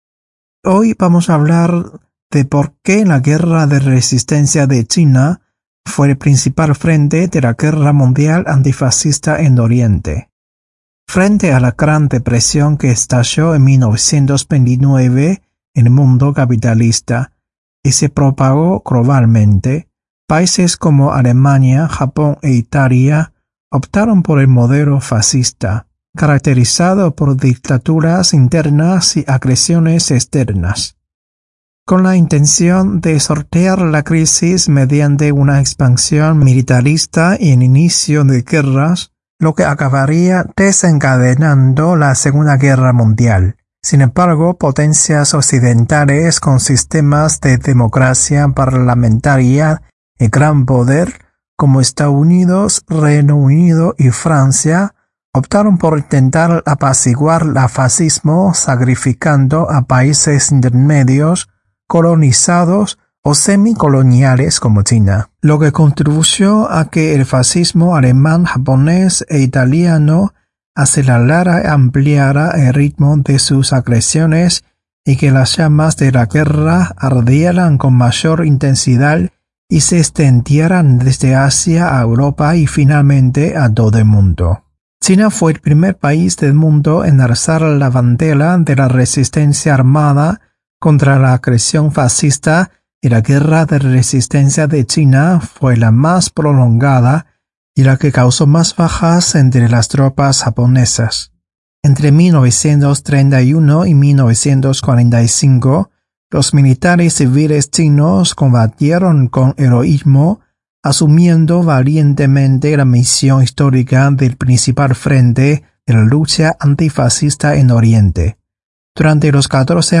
Estos 4 episodios fueron emitidos en nuestro programa radial, Clave China, los días 17, 24 y 31 de Agosto y 7 de septiembre del 2025